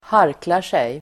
Ladda ner uttalet
Folkets service: harkla sig harkla sig verb, hawk , clear one's throat Grammatikkommentar: A & Uttal: [²h'ar:klar_sej] Böjningar: harklade sig, harklat sig, harkla sig, harklar sig Definition: klara strupen